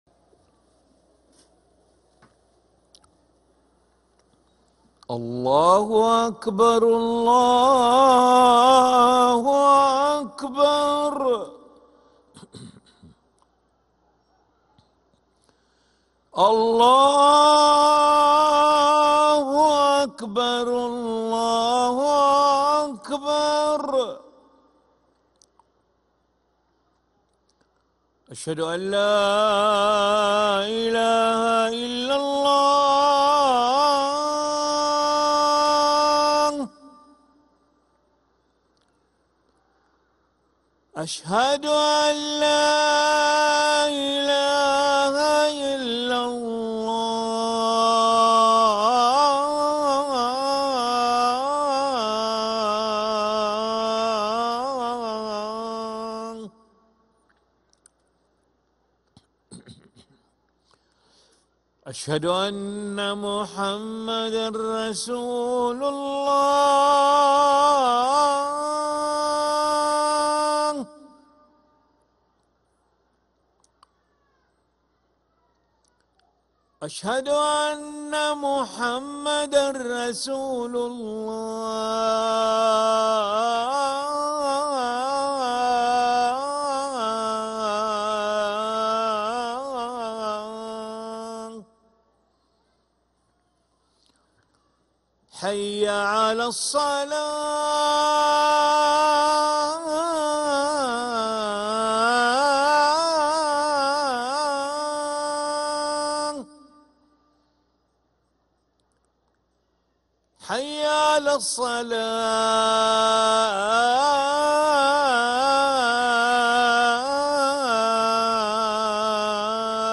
أذان العشاء للمؤذن علي ملا الخميس 19 جمادى الأولى 1446هـ > ١٤٤٦ 🕋 > ركن الأذان 🕋 > المزيد - تلاوات الحرمين